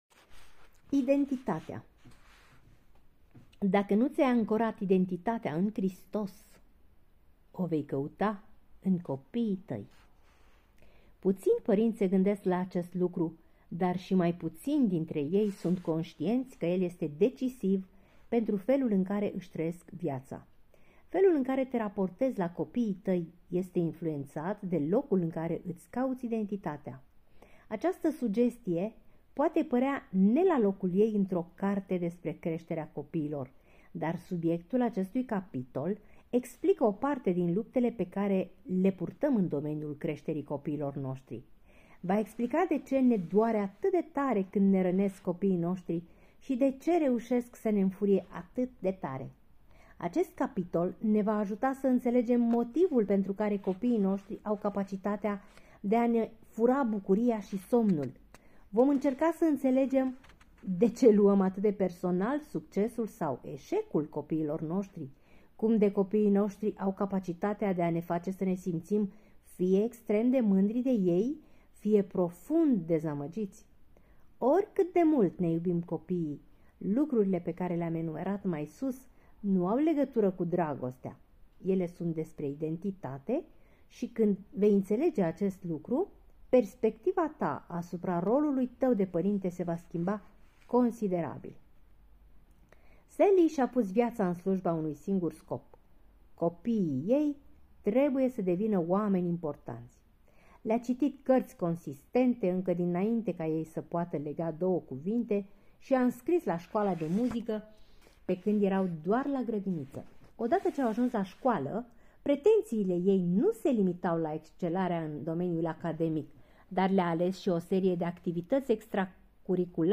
Este începutul celui de-al cincilea capitol al cărții "Pentru părinți - 14 principii care îți pot schimba radical familia" de la Paul David Tripp.